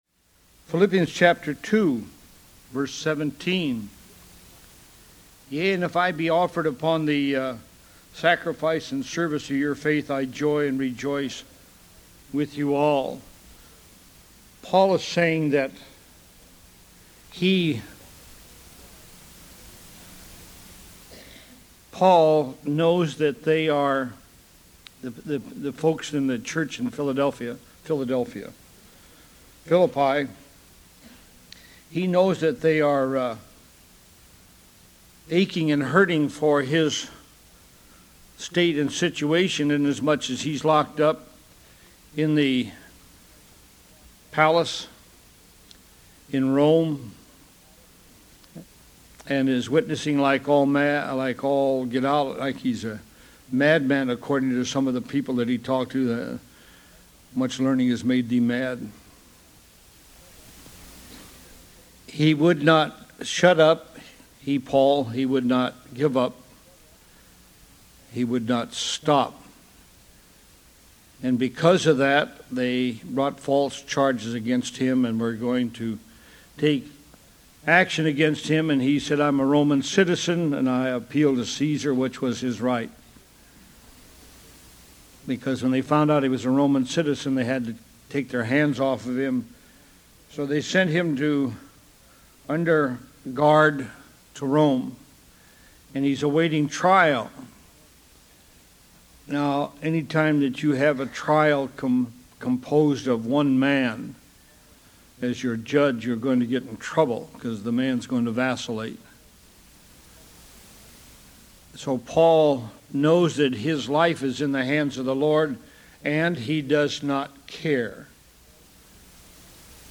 Ministry: Serving the Lord download sermon mp3 download sermon notes Welcome to Calvary Chapel Knoxville!